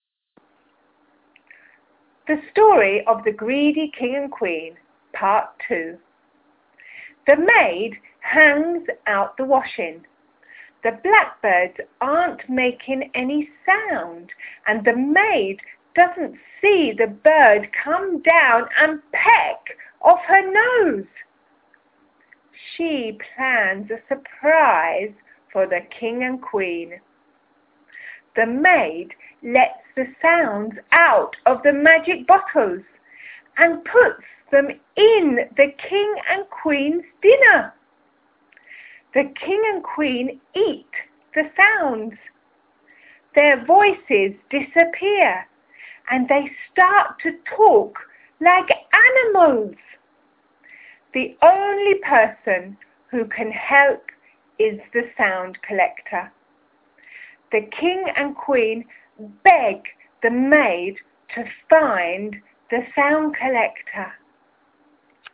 STORY, PART 2